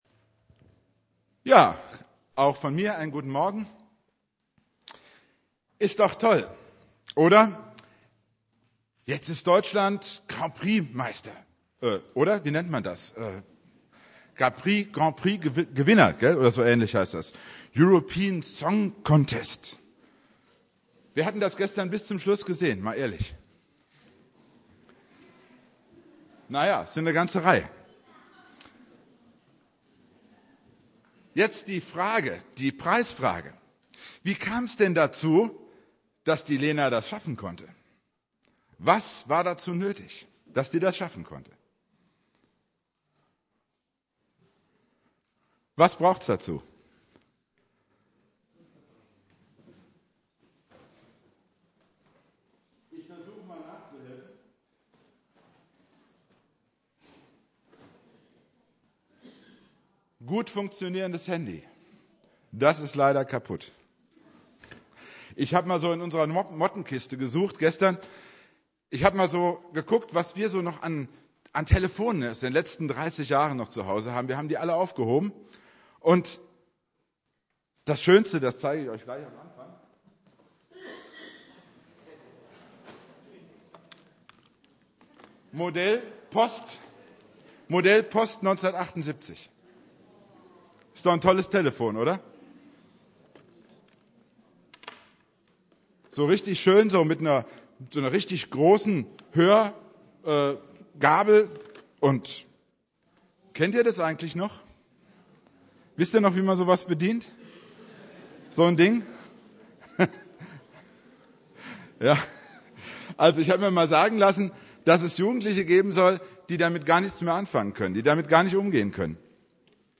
Predigt
Bittet, so wird euch gegeben (Konfirmationsgottesdienst) Bibeltext: Lukas 11,5-13